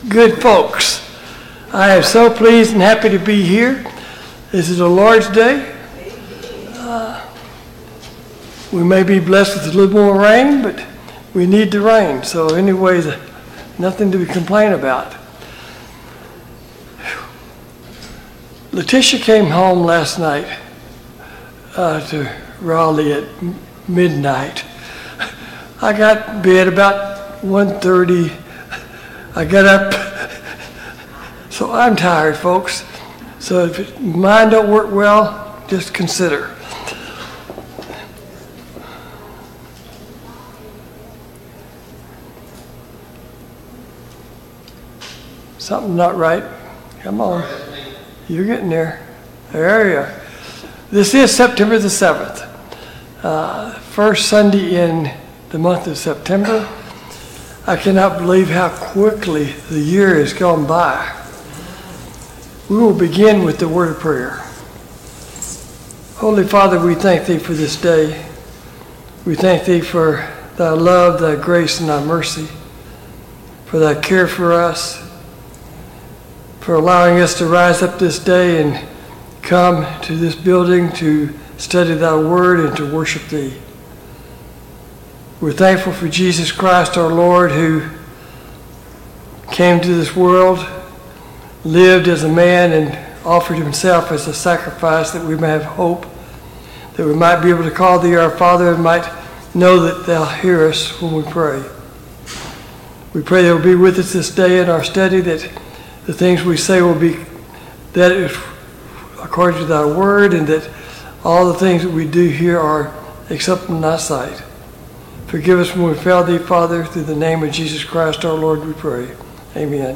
Passage: Mark 1 - 2 Service Type: Sunday Morning Bible Class